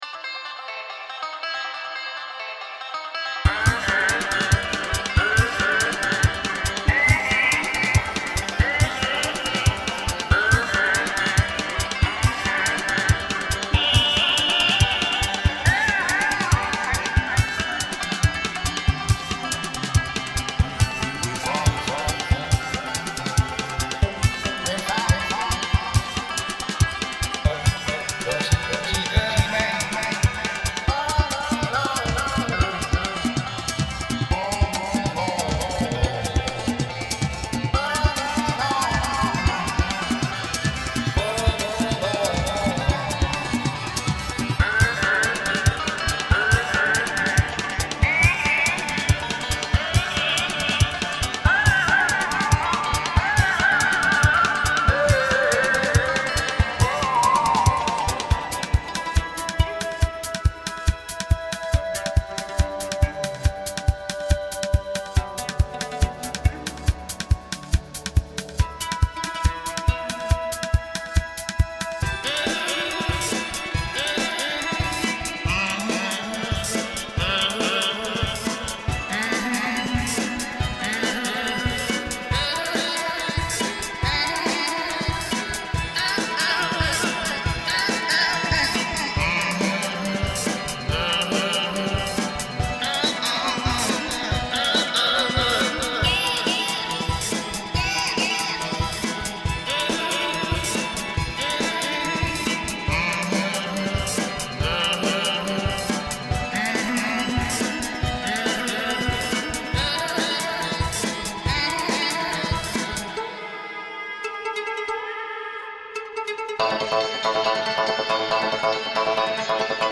The hell of it (instrumental) - Orchestrated: 23rd-29th October 2011.
It took some while to get this one right, and it was only when I hit on the idea of adding loop vocals to it that it sounded convincing.